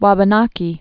(wäbə-näkē)